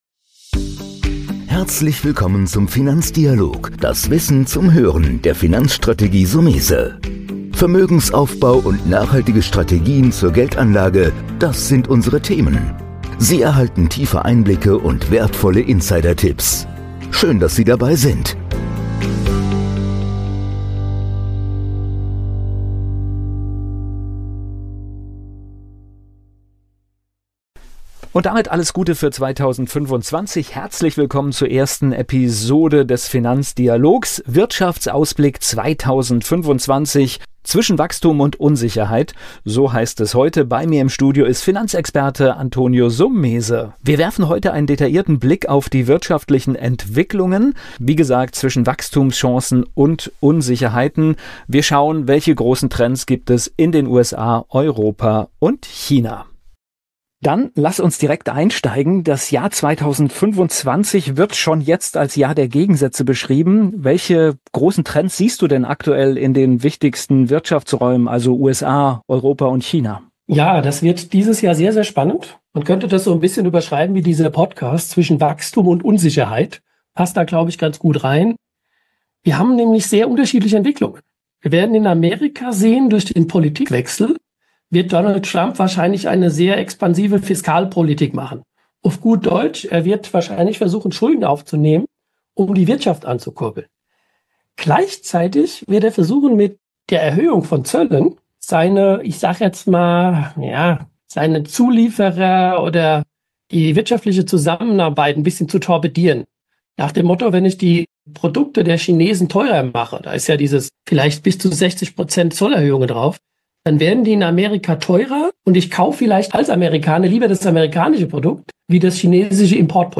Wirtschaftsausblick 2025 – Zwischen Wachstum und Unsicherheit — Interview